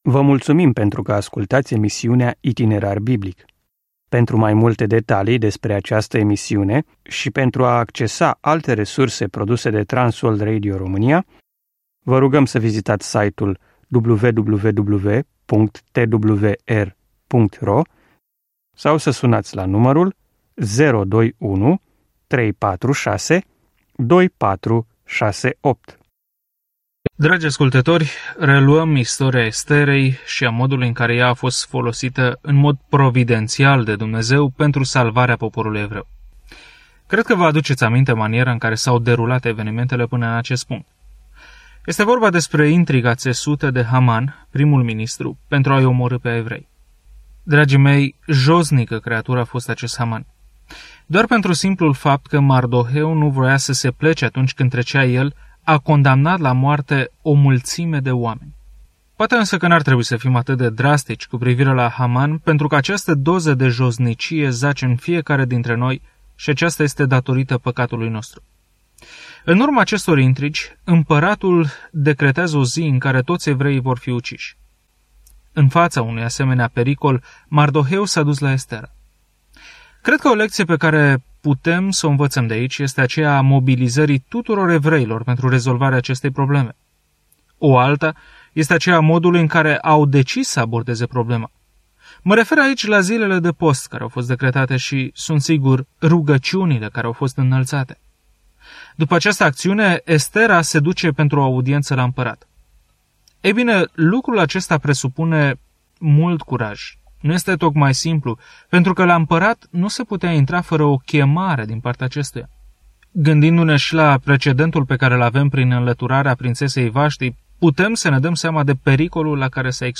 Scriptura Estera 5:5-14 Estera 6 Ziua 6 Începe acest plan Ziua 8 Despre acest plan Dumnezeu a avut întotdeauna grijă de poporul său, chiar și ori de câte ori comploturile de genocid amenință dispariția lor; o poveste uimitoare despre cum o fată evreică se confruntă cu cea mai puternică persoană în viață pentru a cere ajutor. Călătoriți zilnic prin Estera în timp ce ascultați studiul audio și citiți versete selectate din Cuvântul lui Dumnezeu.